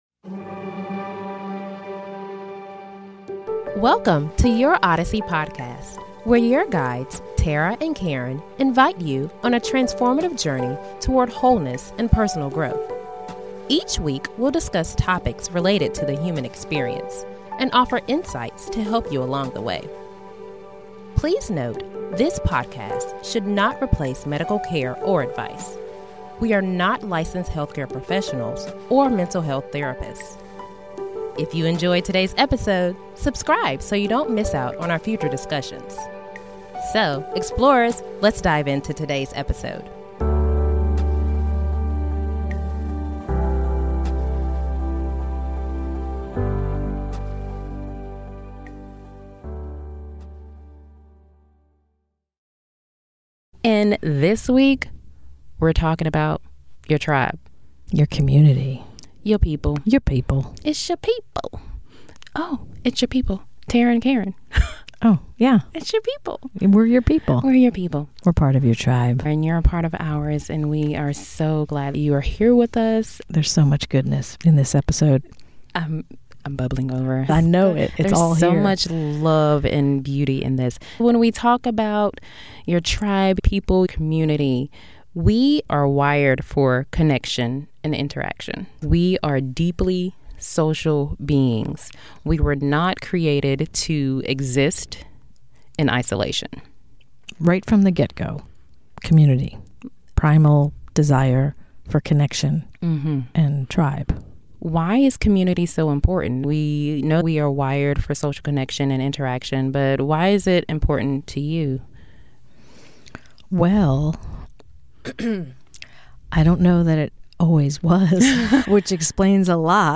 a heartfelt discussion on the importance of community